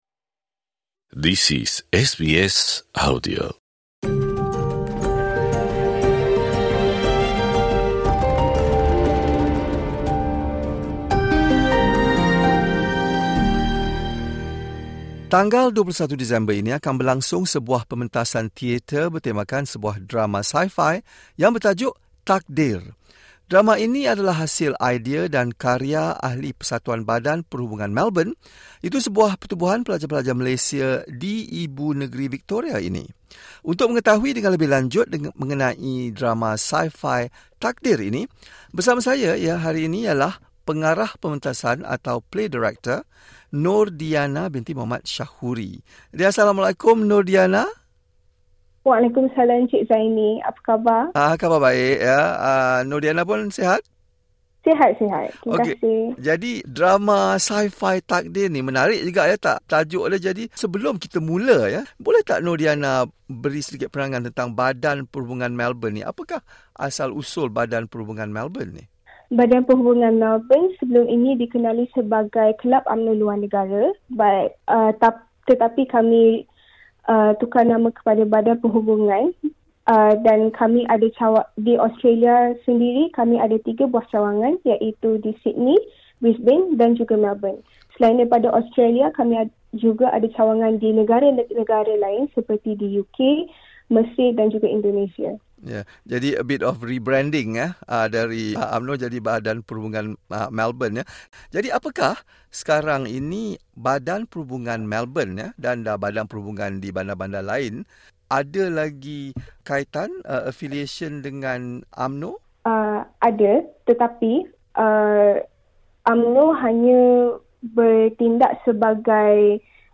perbualan